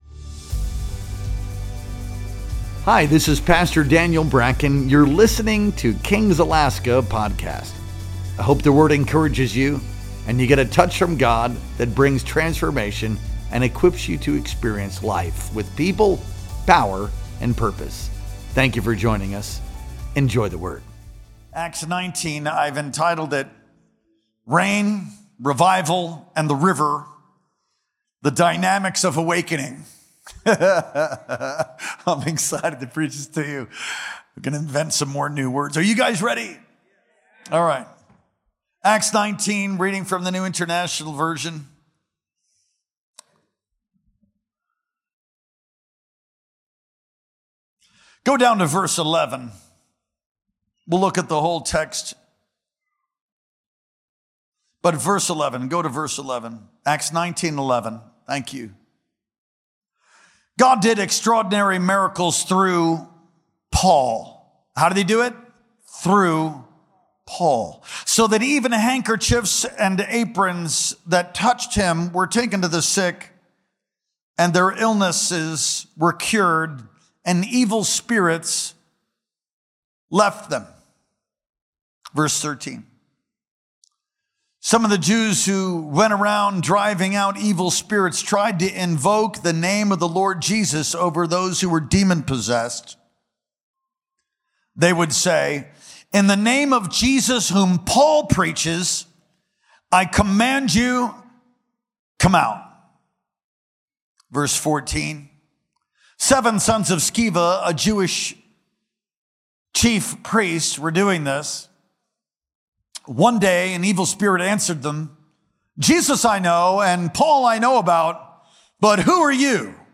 Our Wednesday Night Worship Experience streamed live on October 15th, 2025.